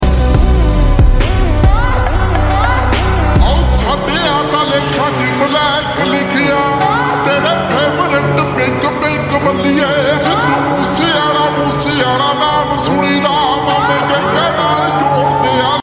Honda CG 125 AHL 3 Silencer sound effects free download
Honda CG 125 AHL-3 Silencer Sound